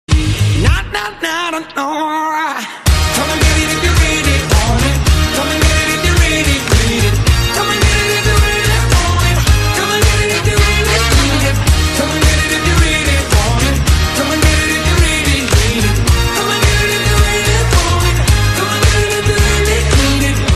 M4R铃声, MP3铃声, 欧美歌曲 97 首发日期：2018-05-15 06:37 星期二